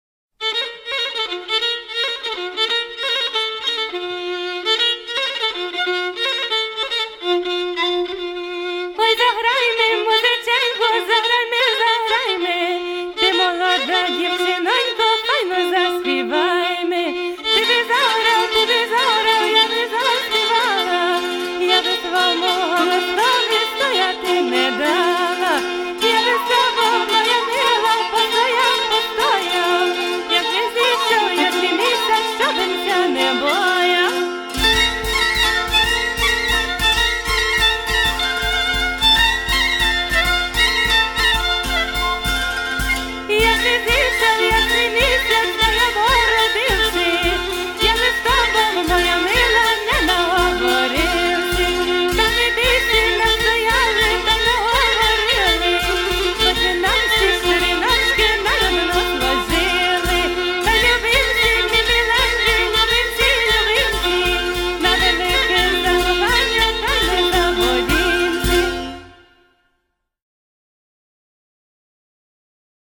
(Folk/Eternal)